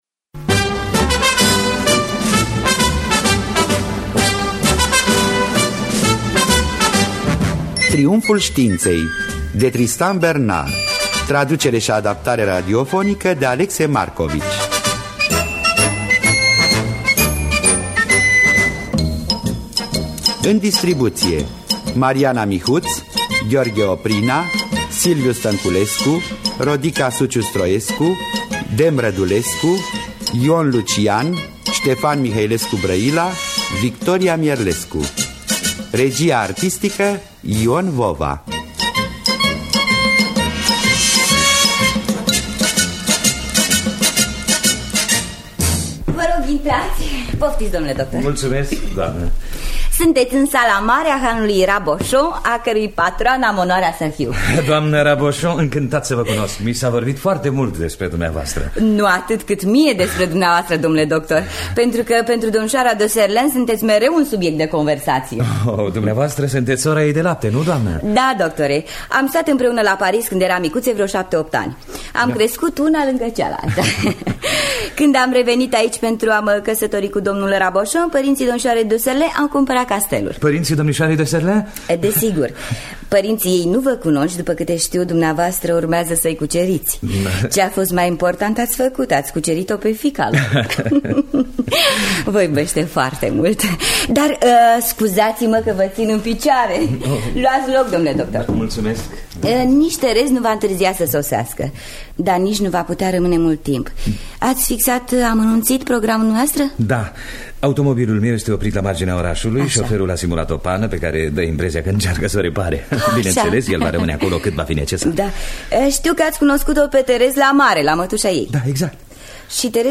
Tristan Bernard (Paul Bernard) – Triumful Stiintei (1978) – Teatru Radiofonic Online